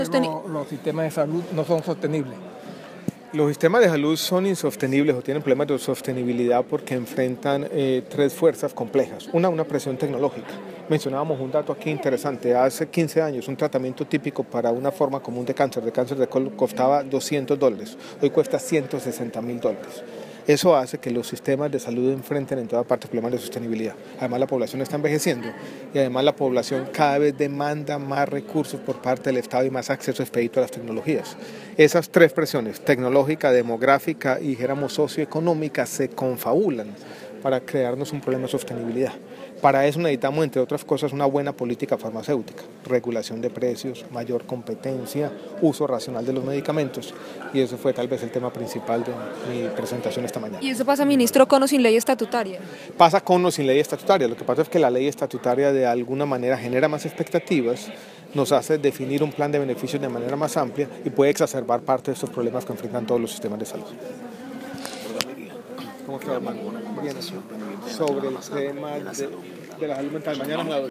-    El ministro Alejandro Gaviria participó en el cierre del 22º Foro Farmacéutico de la ANDI en Cartagena.